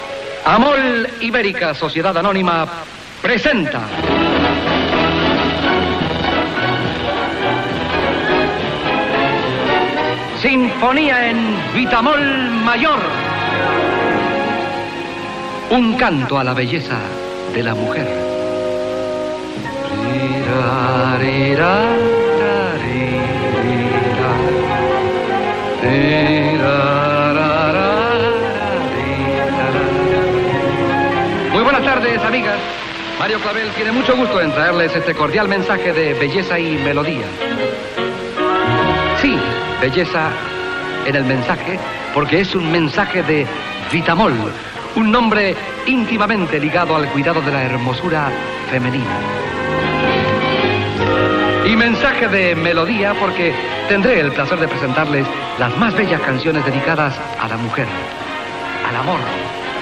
Fragment extret del programa "La radio con botas", emès per Radio 5 l'any 1991